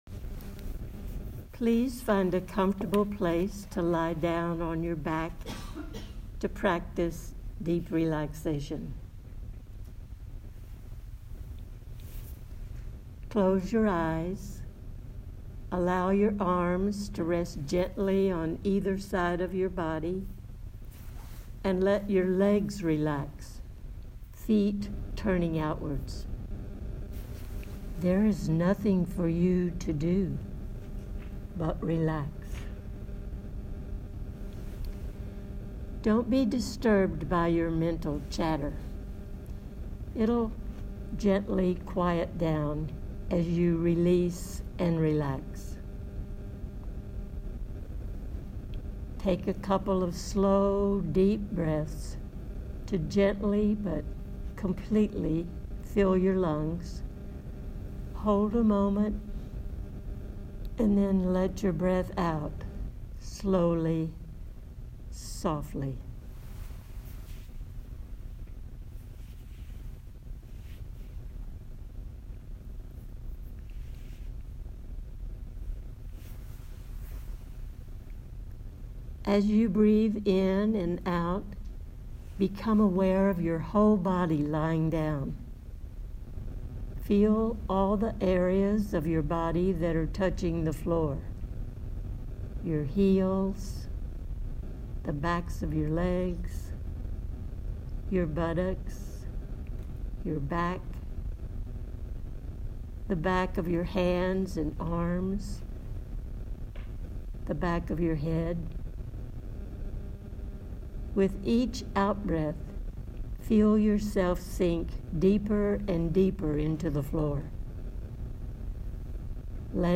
Deep Relaxation